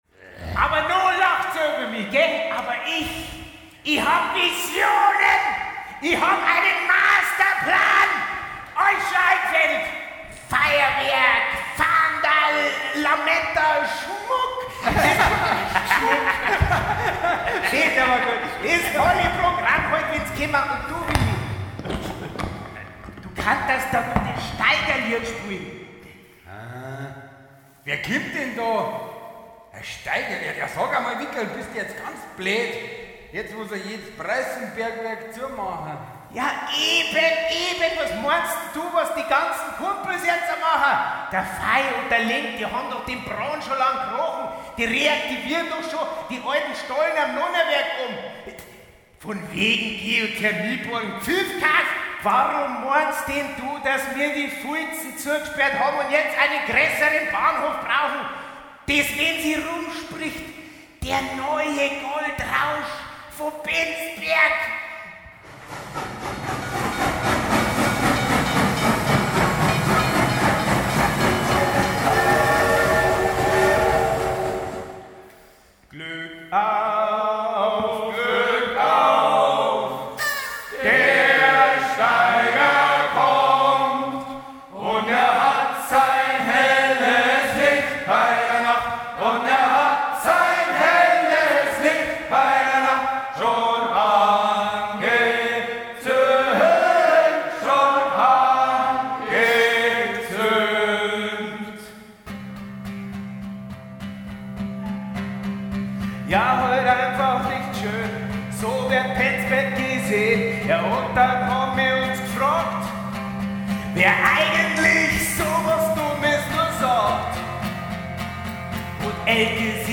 Am Ende eine zweifelhafte, aber mitreißende Parole: „Penzberg wird blühn, wir machen das Bergwerk wieder auf.“
OVTPStkb19-Singspiel-Finale.mp3